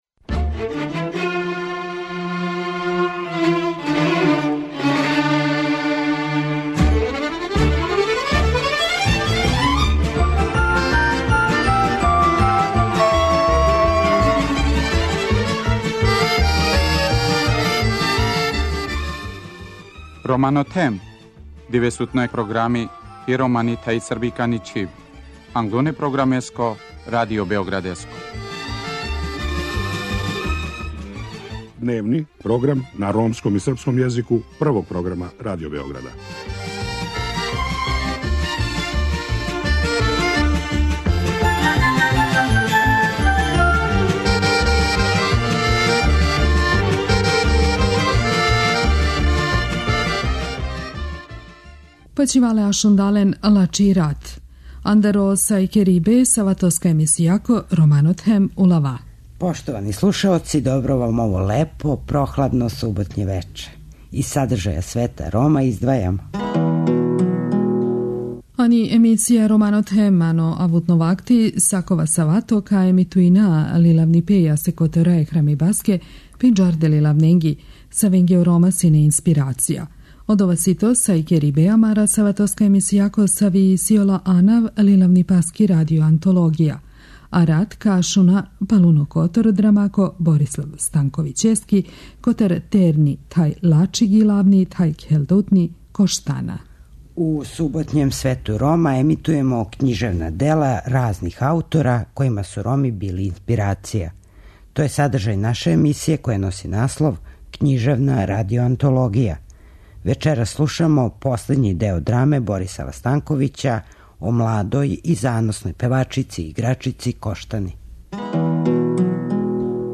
Вечерас слушамо последњи део драме Борисава Станковића о младој и заносној певачици и играчици Коштани.